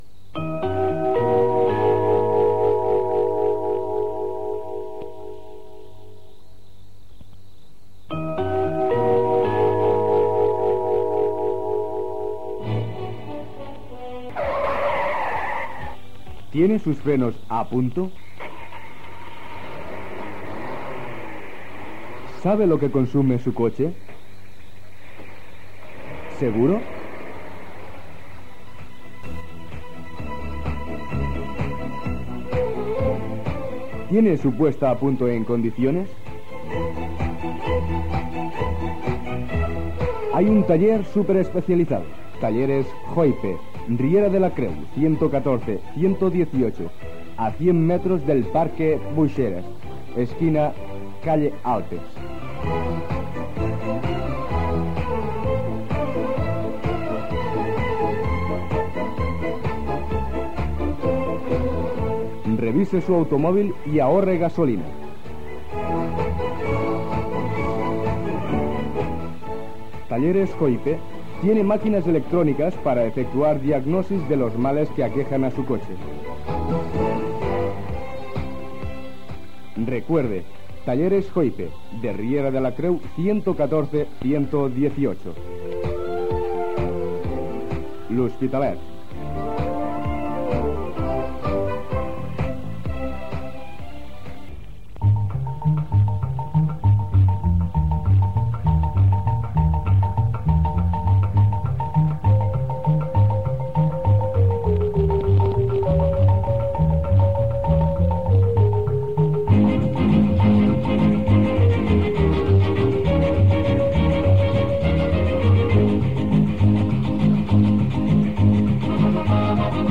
Sintonia, publicitat i lectura d'una carta.